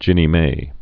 (jĭnē mā)